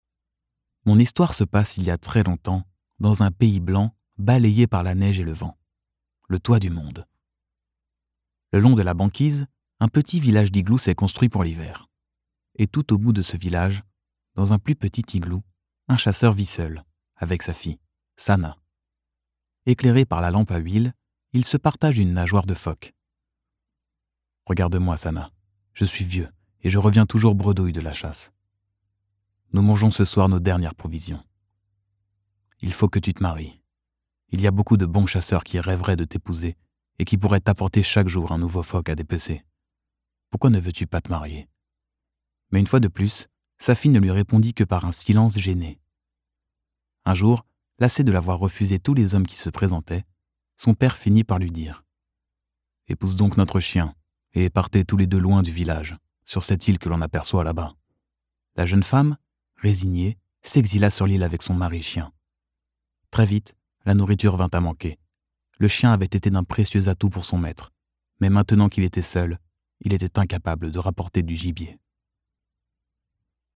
Conte pour enfants
Ténor